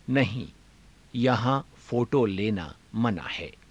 ゆっくり ふつう